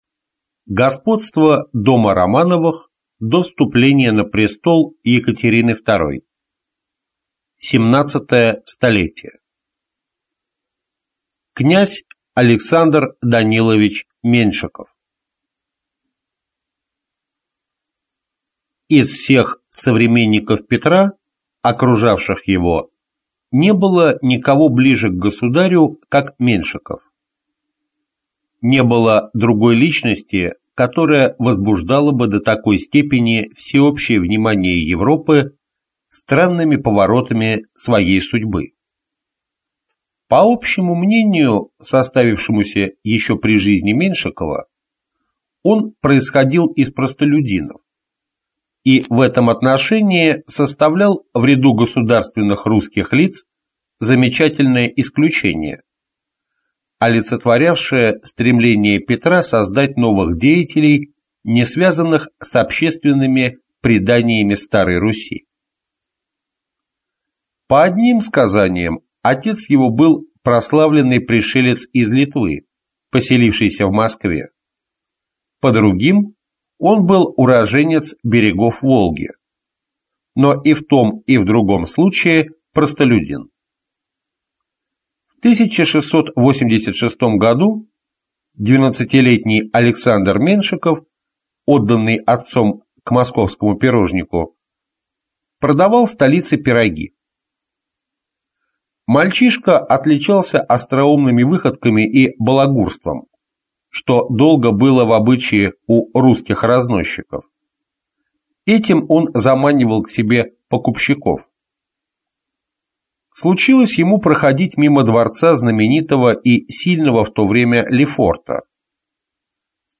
Аудиокнига Князь Александр Данилович Меншиков | Библиотека аудиокниг